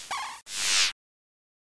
Zap1.ogg